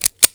tang.wav